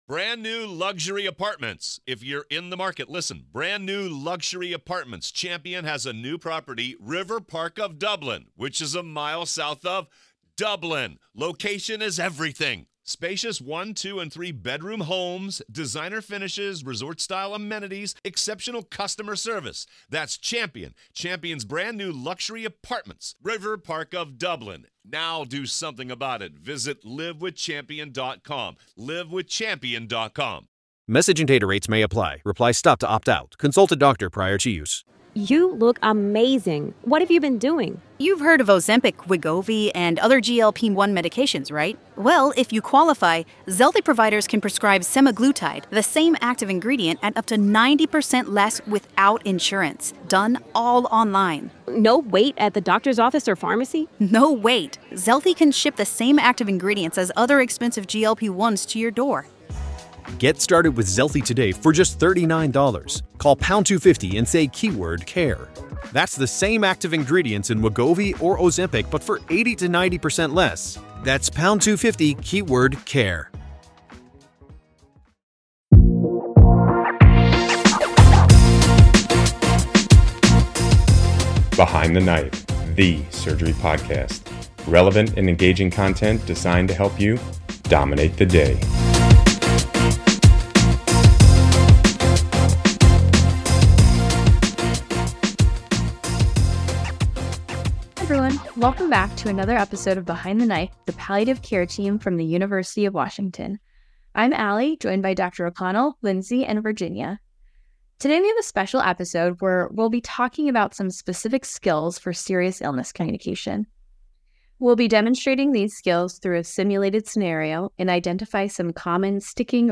Join the surgical palliative care team from the University of Washington as we role play a difficult conversation with a standardized patient. We will identify common challenges that arise and discuss key skills to navigate these situations.